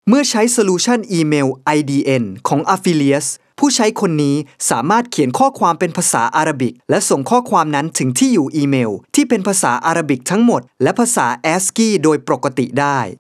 i have a home recording studio with considerable audio equipments such as Nueman TLM 103 microphone, Focusrite Class A preamp, KRK V6 studio monitor and Digidesign Mbox.
I am a professional Thai voice talent.
Sprechprobe: eLearning (Muttersprache):